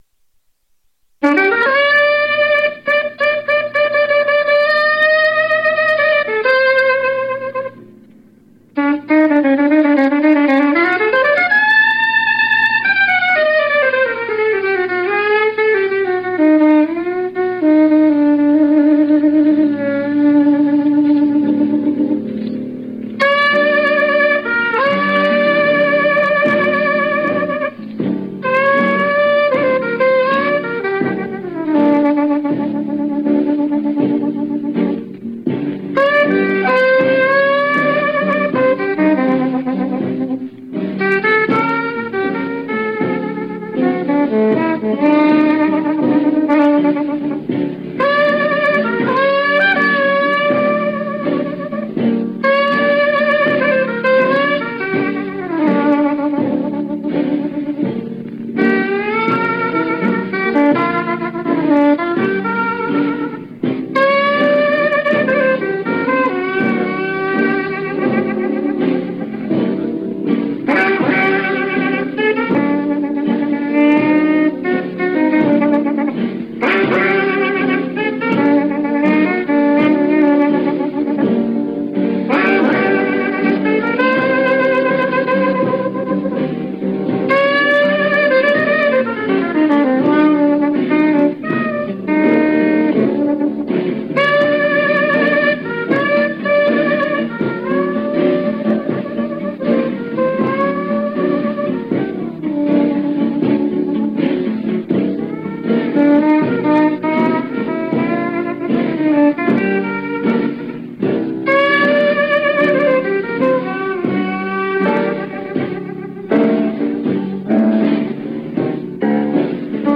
sax soprane